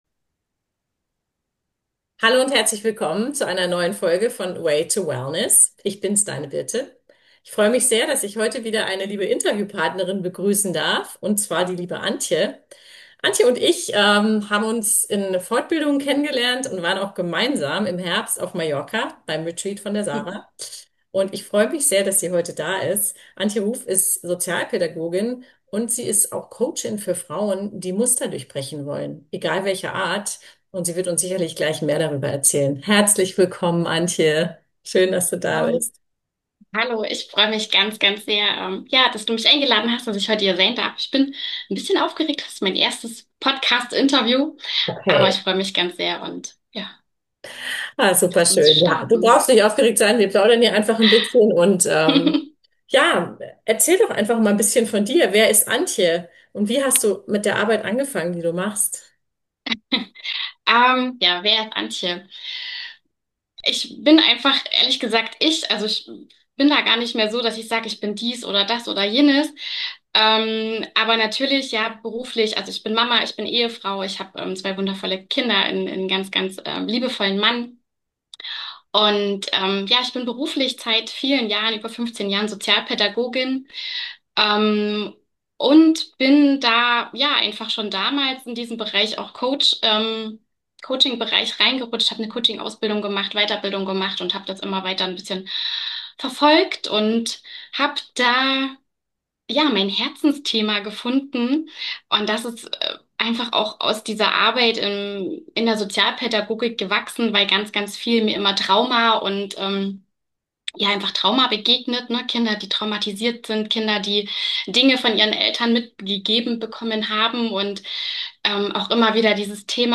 Eine ruhige, tiefgehende Folge für die Feiertage – zum Innehalten, Verstehen und Neu-Ausrichten.